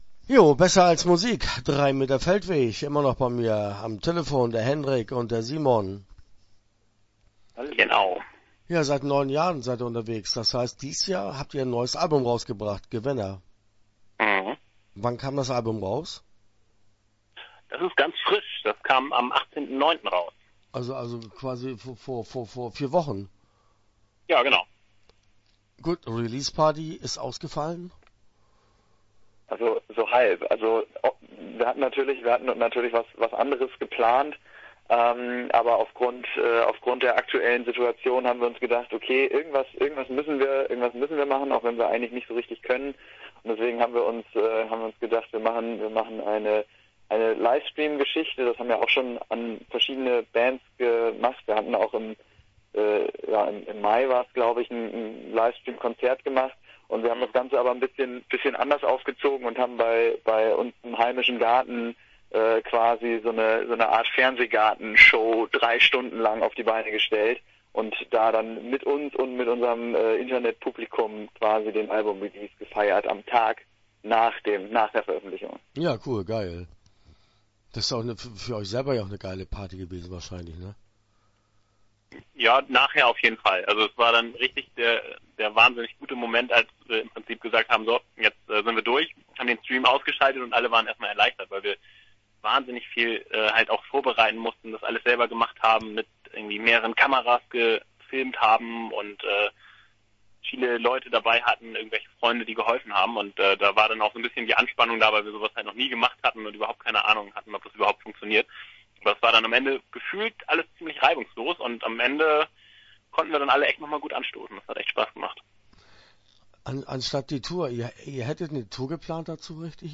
Drei Meter Feldweg - Interview Teil 1 (10:49)